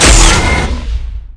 ImpactAltFireRelease.mp3